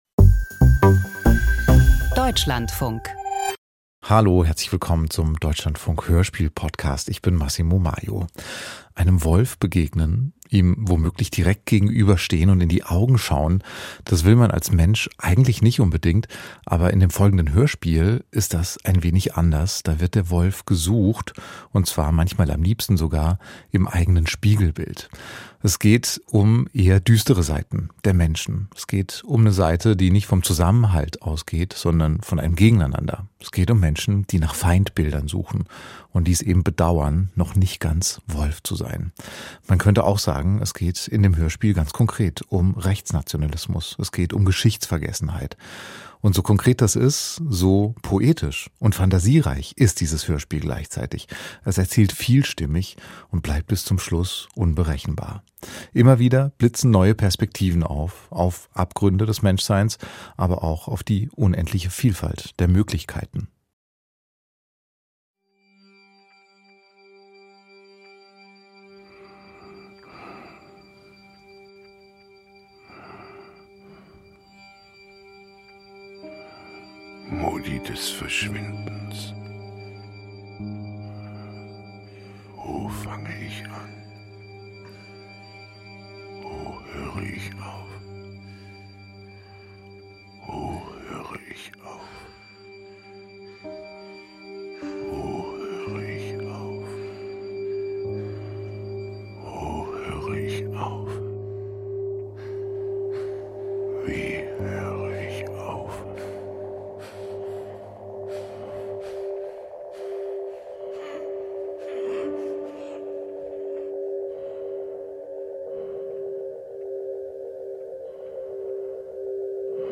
Hörspiel über nationalistische Gewalt - Wolfwelt
Verdrängte Verantwortung und gepanzerte Körper: eine vielstimmige Erzählung, kombiniert mit moderner Gewaltforschung